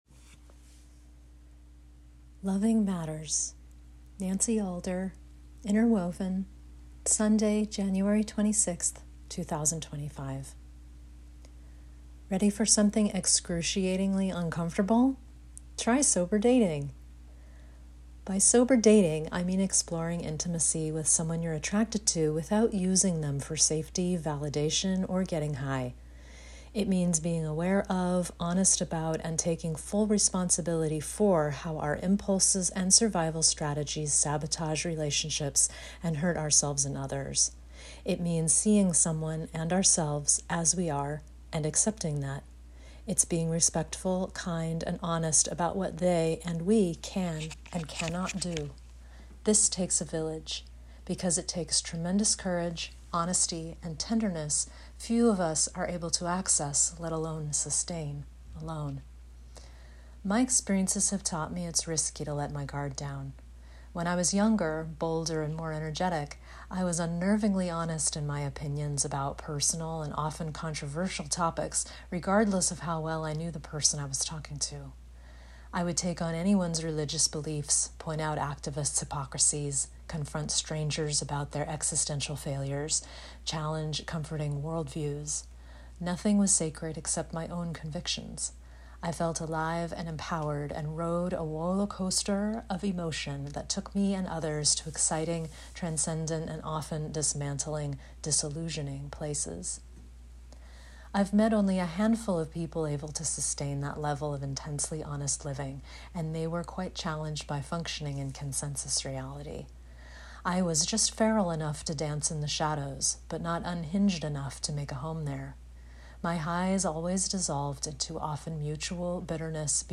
Enjoy this 12-minute read, or let me read it to you on your commute, neighborhood walk, or cooking session!